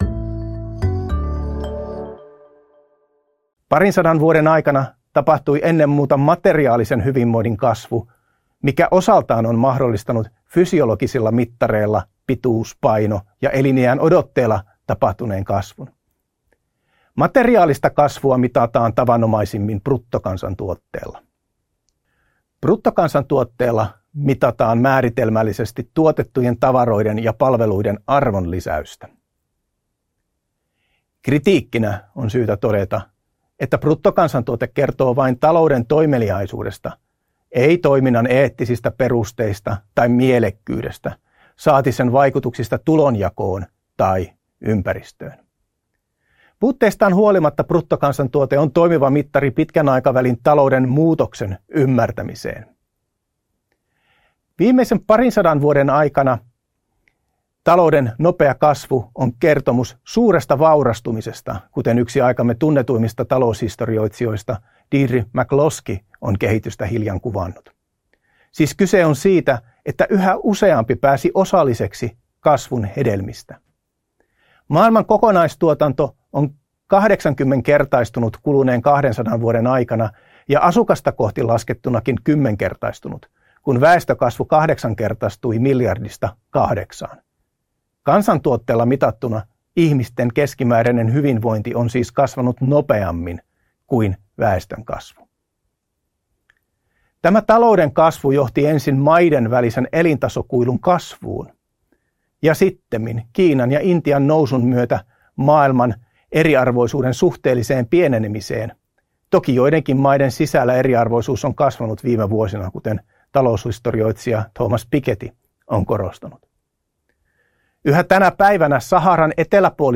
Rector's Speech : Societal Changes in Wellbeing — Moniviestin